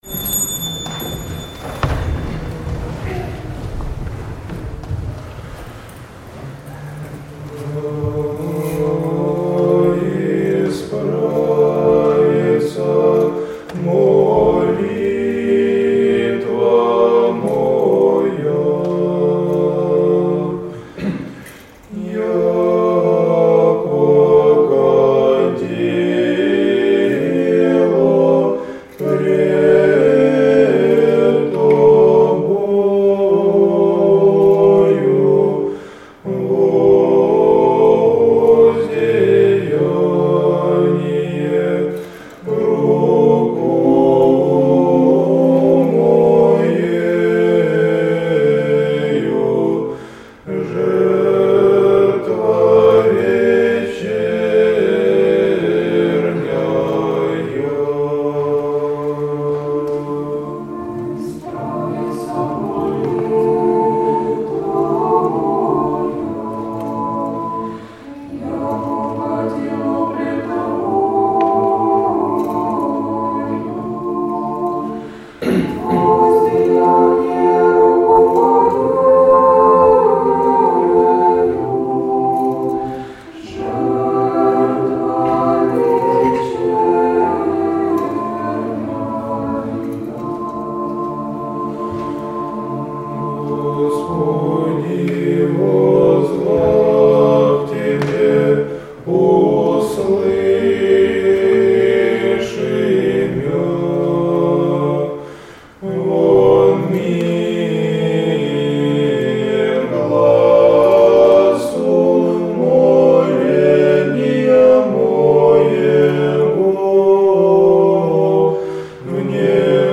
Литургия Преждеосвященных Даров 08 марта 2017 года
Алтарники храма исполнили «Да исправится молитва моя» М. А. Гольтисона.